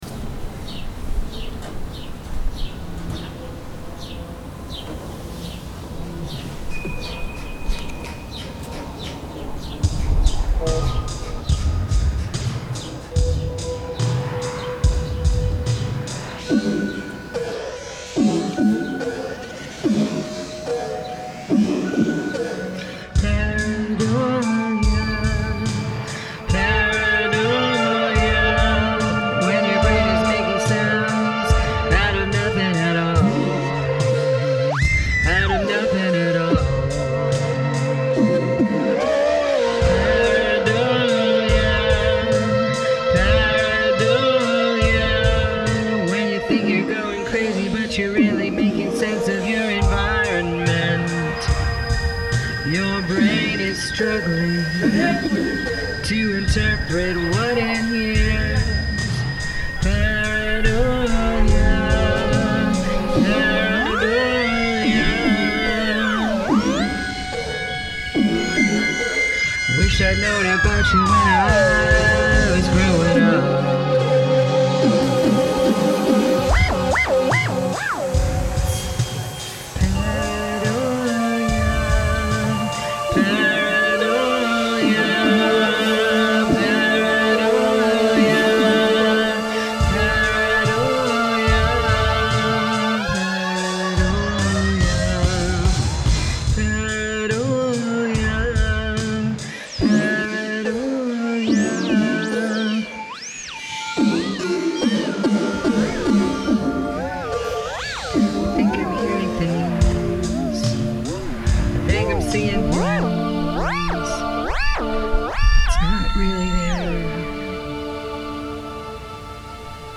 Use of field recording
vocals, Omnichord
“foghorns”
“sirens”
“electric sawing”